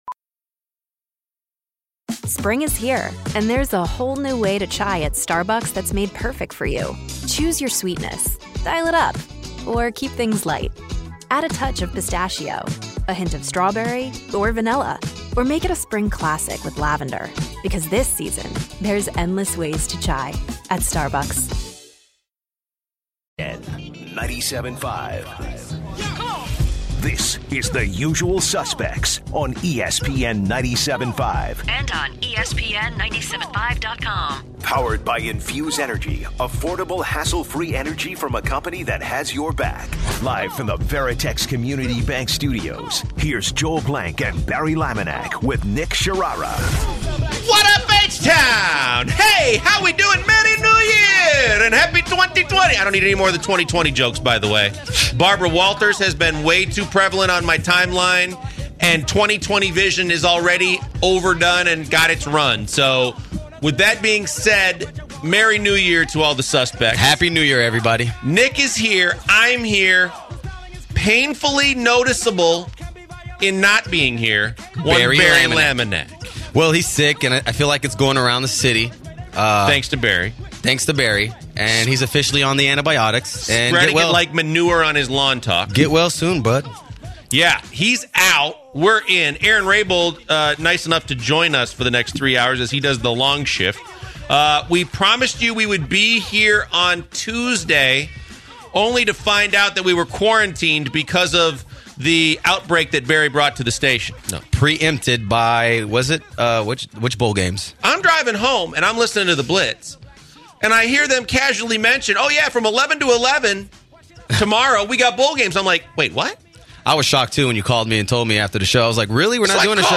The guys talked about a few of the bowl games and a caller called to discuss Michigan and BigTen Specifically. Lastly, they talked about how much easier it was to shop on New Years’ day during the holidays considering everyone usually has a long night on New Years’ Eve.